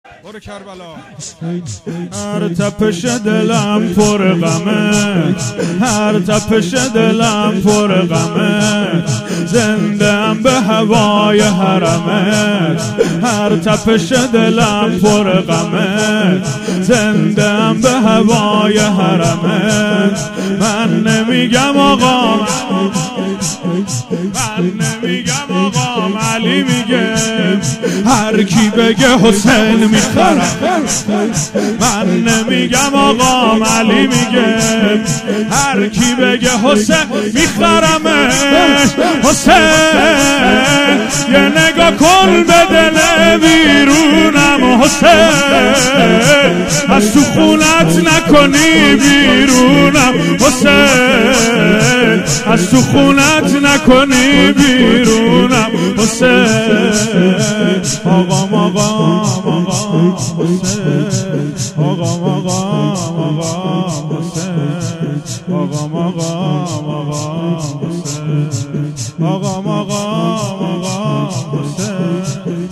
مداحی شور
ایام فاطمیه اول سال1441#مراسم_شب_پنجم_آخر
شور